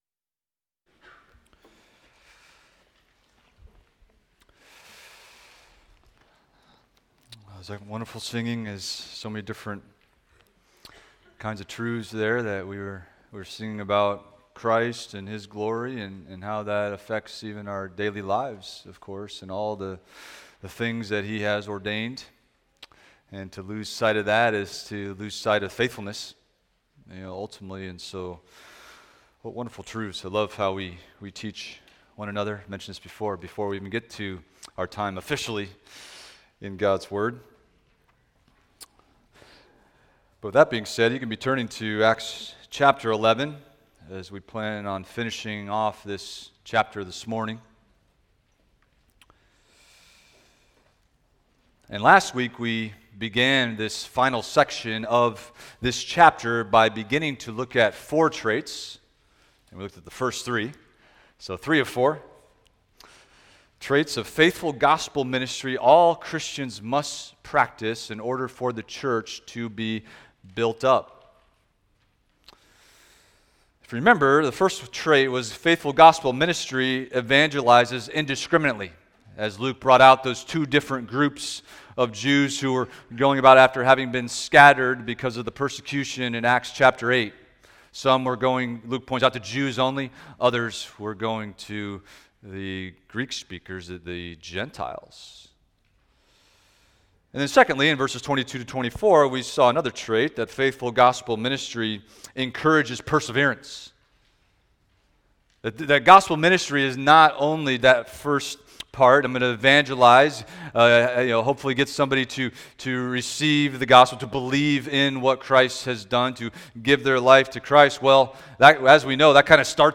Expository Preaching from First Peter – 1 Peter 1:6-9 Rejoicing Greatly in Hope